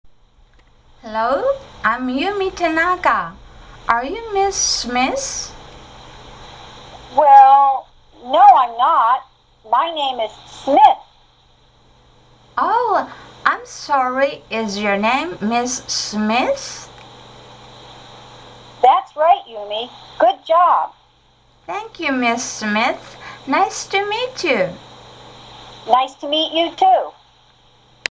会話（リスニング用音声）